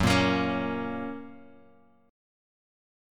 F# chord {2 1 x 3 2 x} chord
Fsharp-Major-Fsharp-2,1,x,3,2,x.m4a